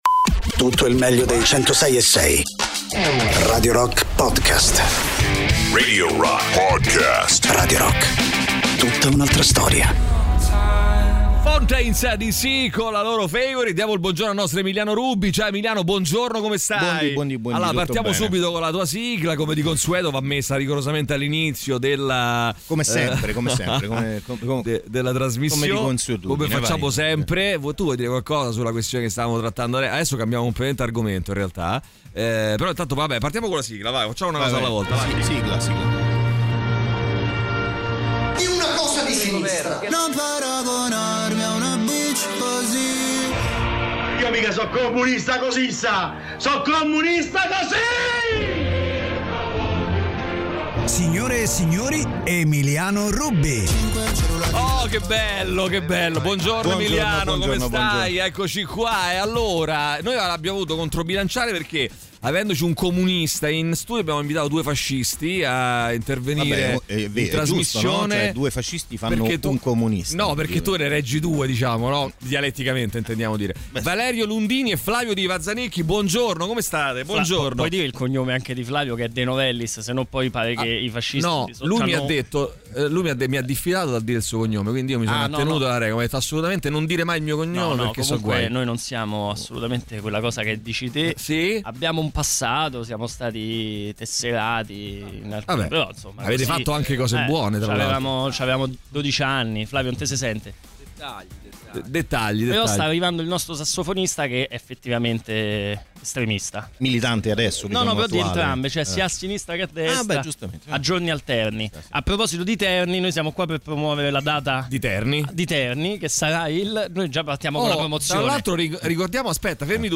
Interviste: Valerio Lundini & i Vazzanikki (11-07-24)